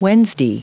Prononciation du mot : wednesday